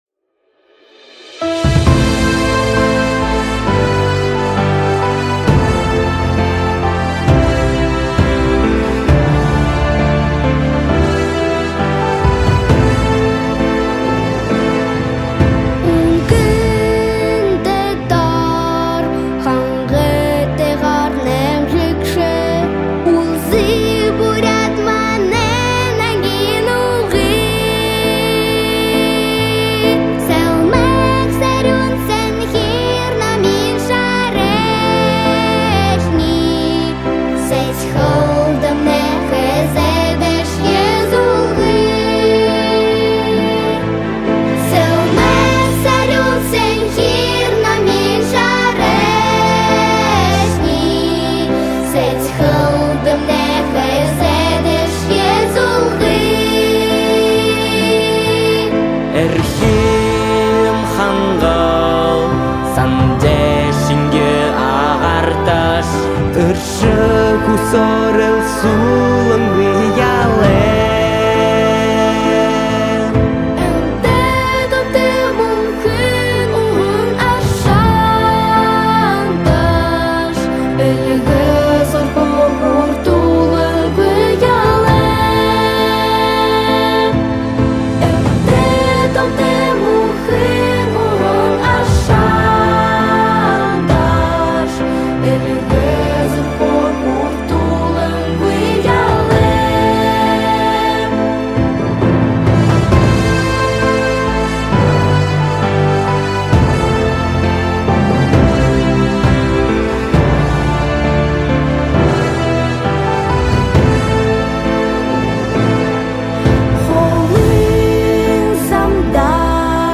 Дети поют